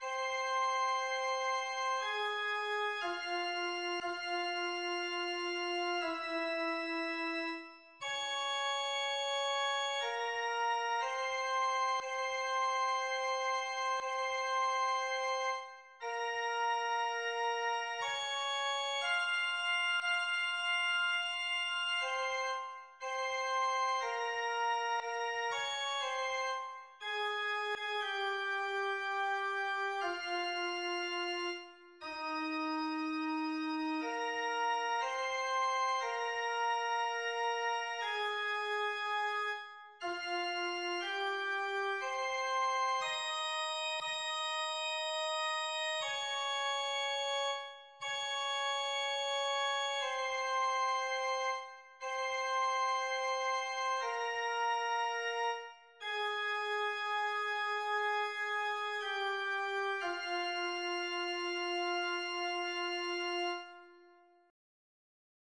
Nas procissões da sexta-feira santa, em Botucatu, a Verônica cantava o lamento abaixo, com música de Alfredo Franklin de Mattos (1923) e versos sacros ("O vos omnes qui transitis per viam attendite e videte si est dolor, sicut dolor meus" - Ó vos que passais pela estrada, dizei se já viste dor maior que a minha)
Áudio Karaoke -